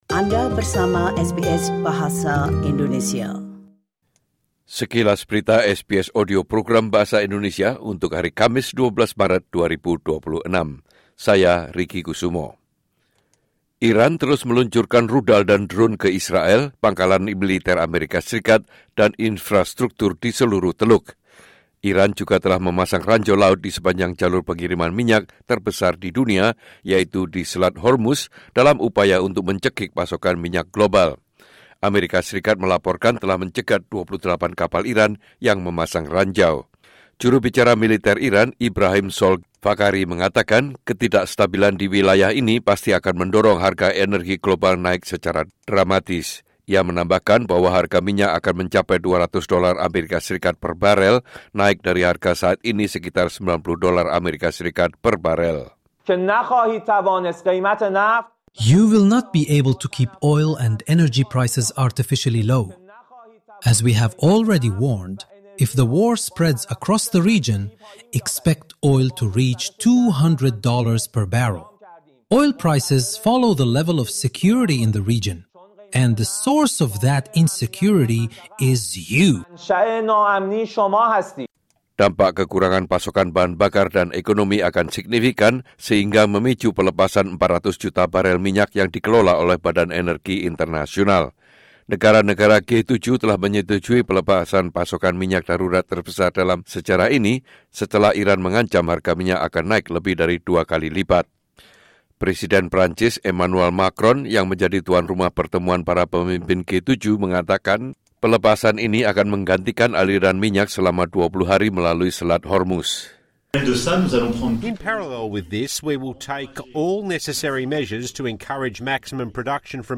Latest News SBS Audio Program Bahasa Indonesia - Thursday 12 March 2026